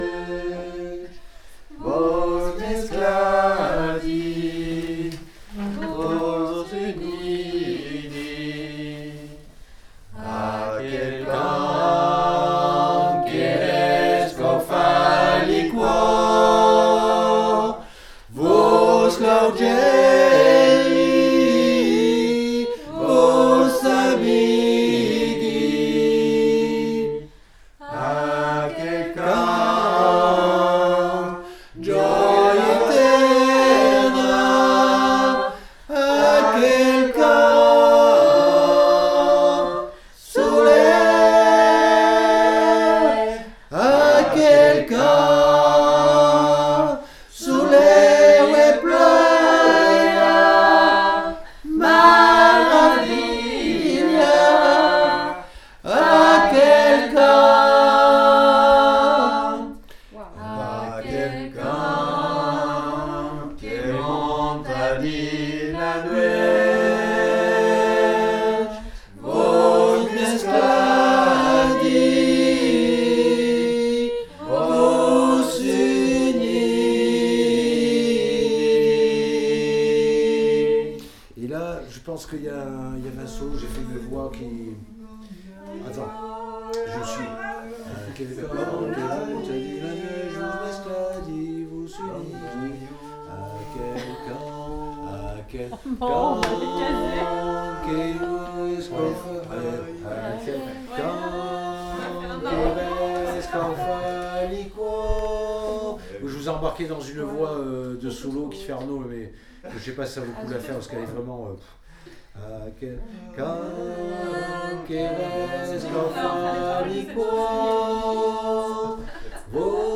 Aquel_cant_VOIX2.mp3